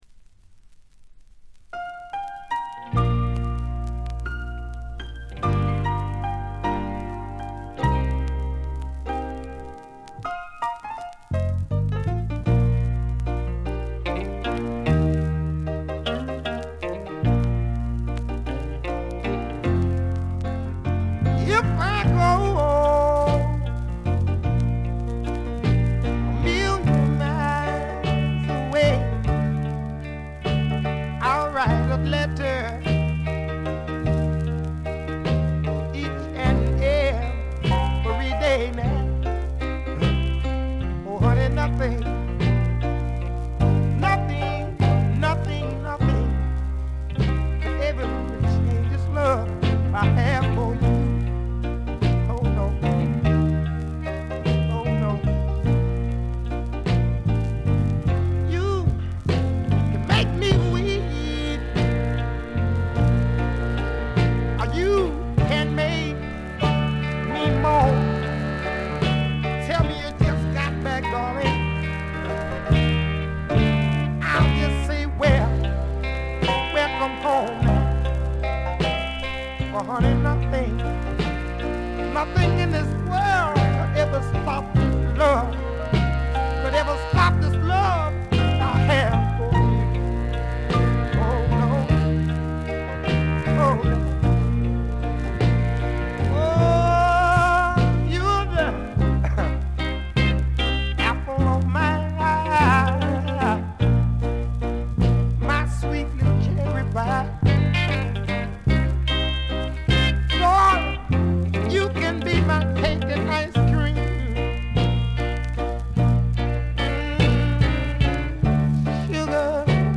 バックグラウンドノイズ（A2序盤が特に目立つ）、チリプチ。
モノラル盤。
試聴曲は現品からの取り込み音源です。